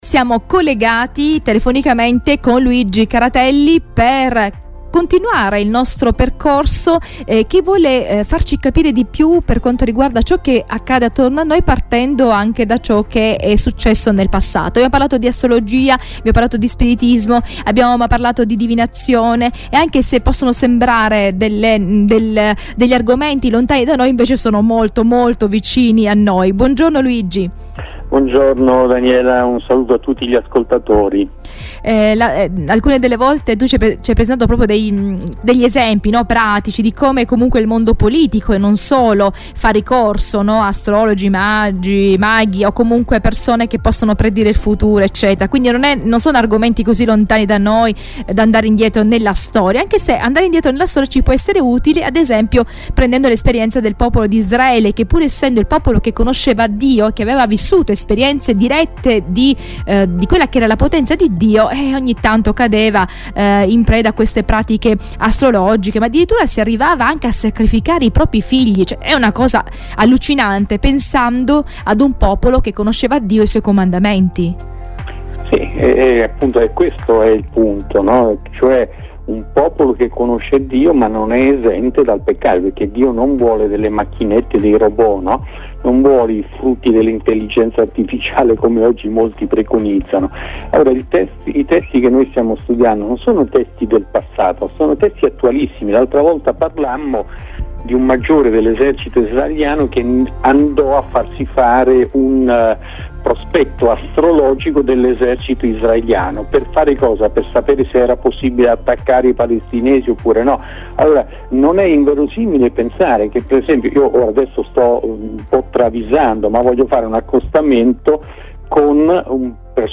in questo dialogo